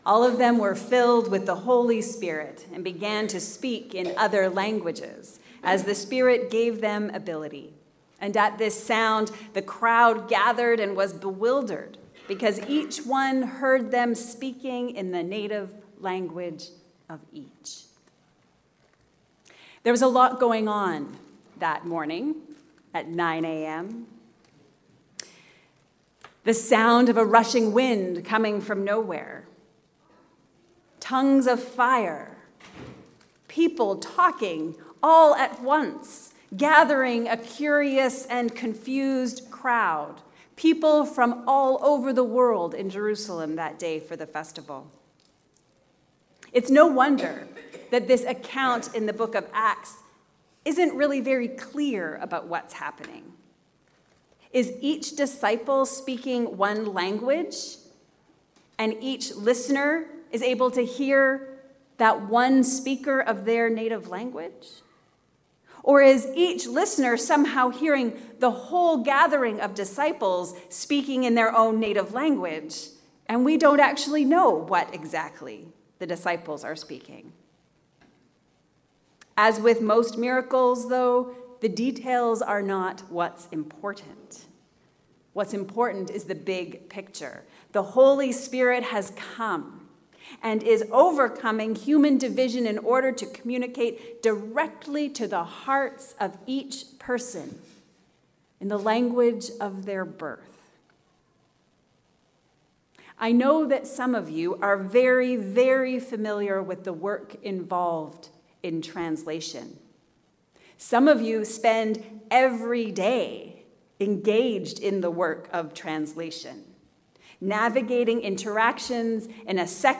The Holy Work of Translation. A sermon for the Feast of Pentecost